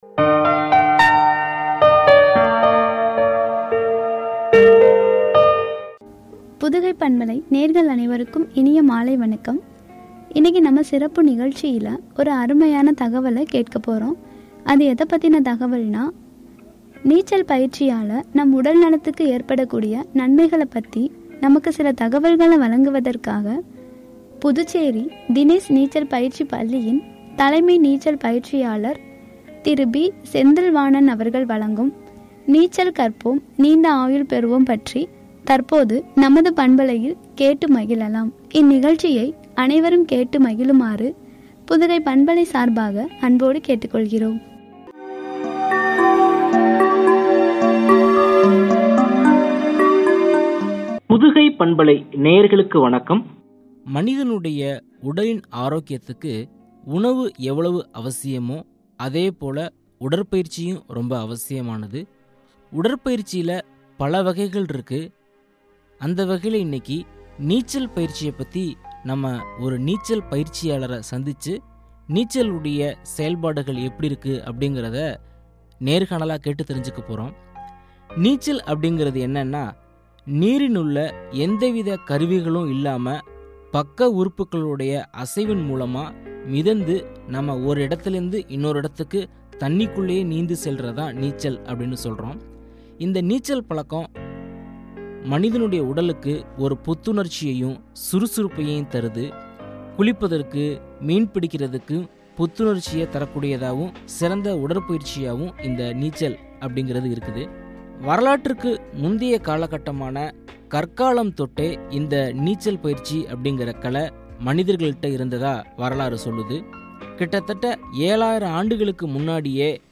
எனும் தலைப்பில் வழங்கிய உரையாடல்.